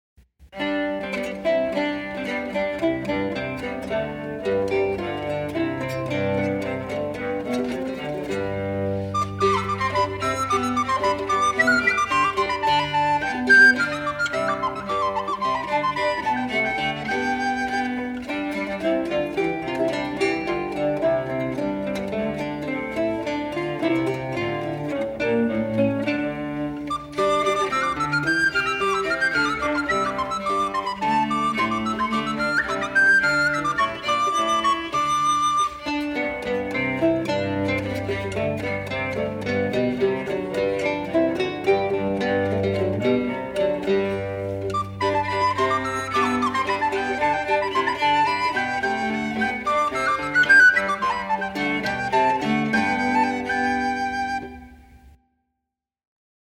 | Dance Ensemble Instrumentalists, session 1973